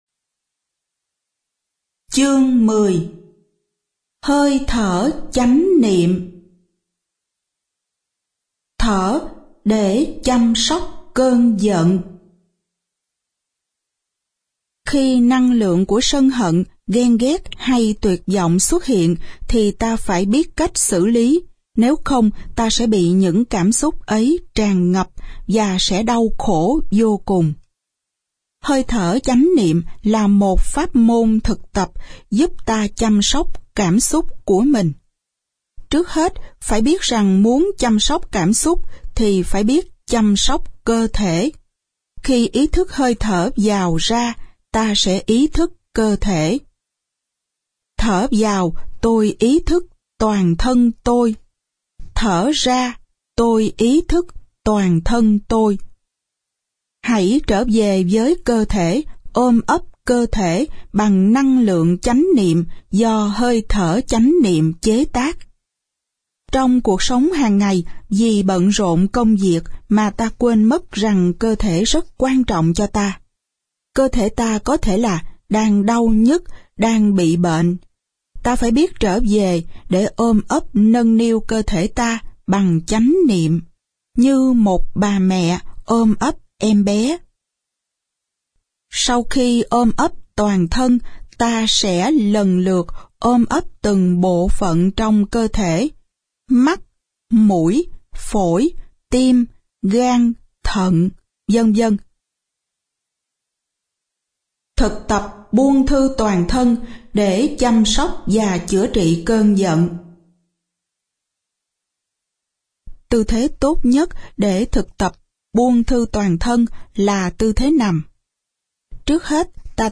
Nghe Giận của Thích Nhất Hạnh - Sách nói Miễn phí